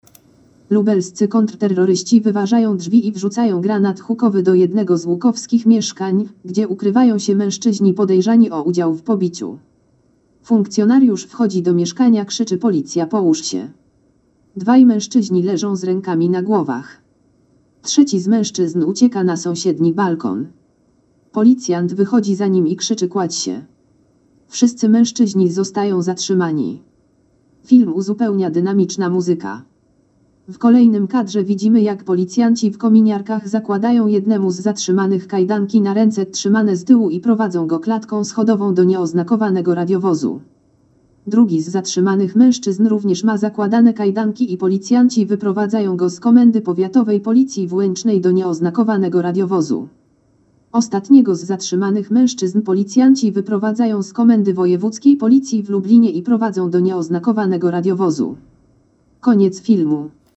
Nagranie audio Audiodeskrypcja filmu "Zatrzymanie trzech mężczyzn do sprawy pobicia z użyciem broni palnej'